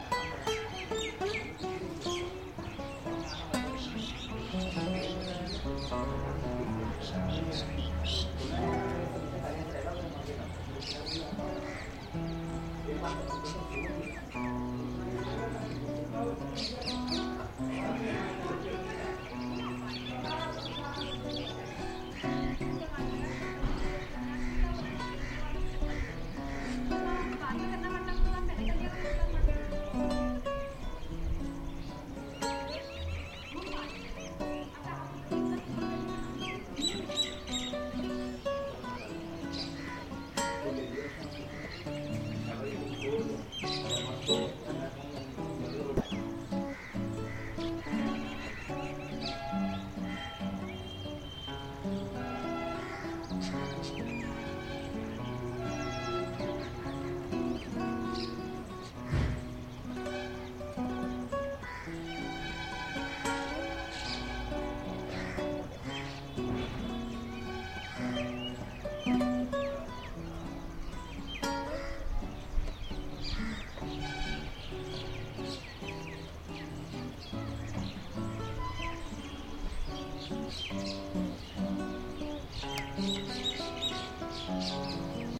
I spend the afternoon on the roof of the hotel, painting the palace of Orchha.
While I am recording the sound, un canadian plays some guitare looking at the landscape.